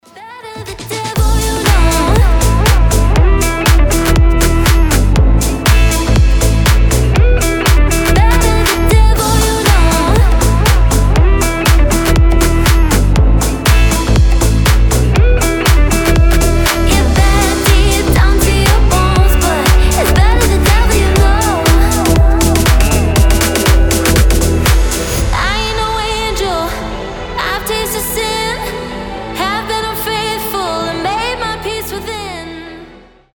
• Качество: 320, Stereo
женский голос
house